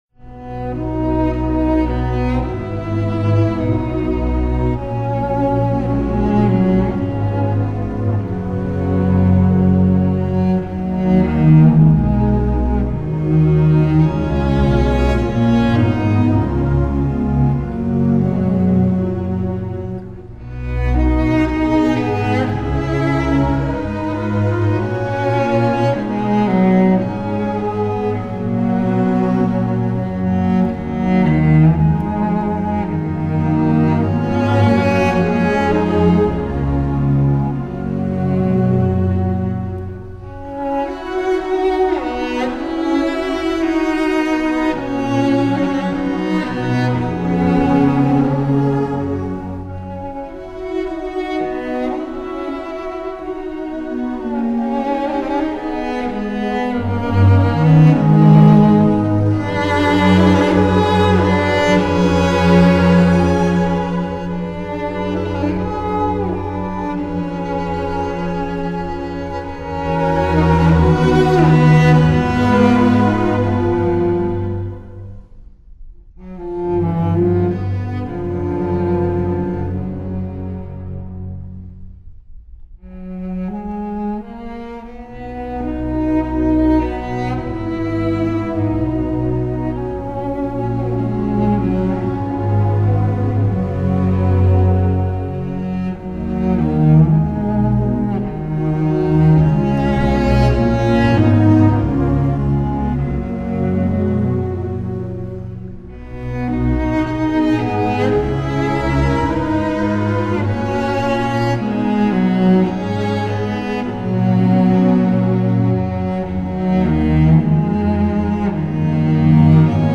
本专辑通过高清晰的现代数码录音，充分展示了史土最重要的两种独奏乐器小提琴和大提琴的惊艳美声。